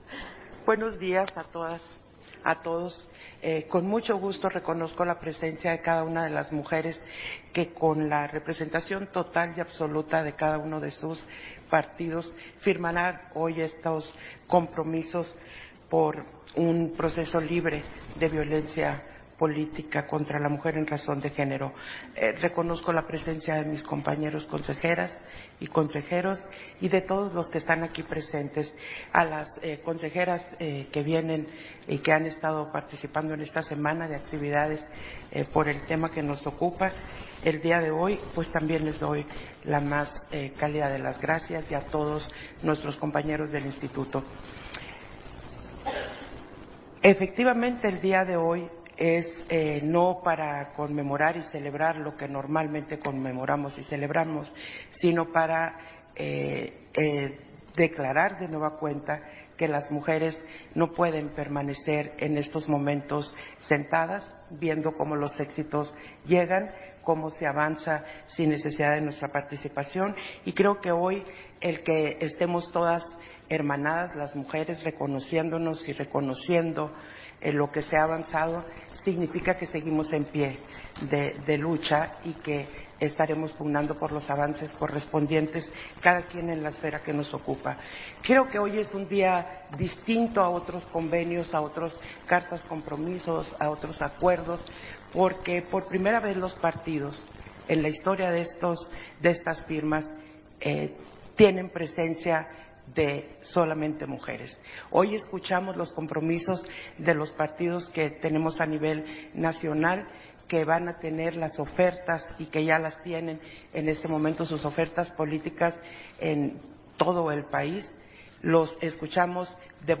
080324_AUDIO_INTERVENCIÓN-CONSEJERA-PDTA.-TADDEI-FIRMA-DE-COMPROMISOS-CON-LOS-PARTIDOS-POLÍTICOS-NACIONALES